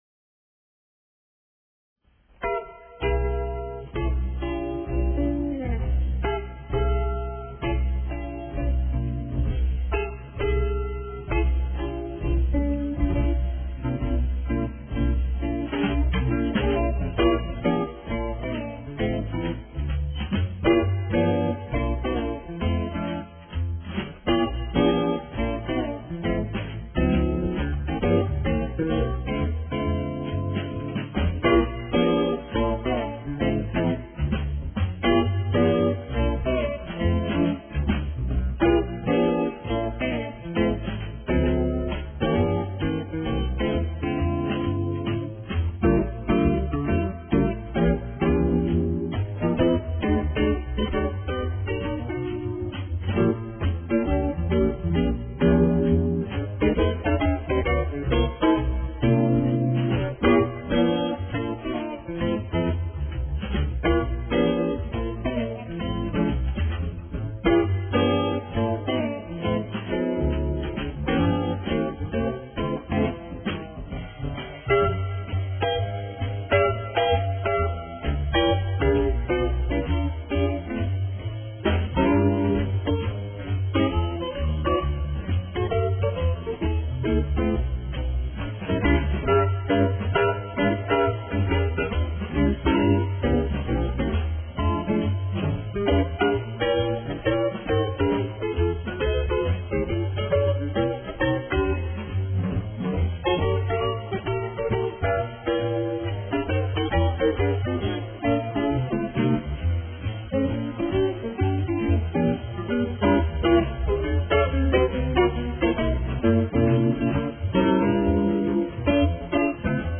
country-jazz guitar instrumentals
Jazzmaster pickin'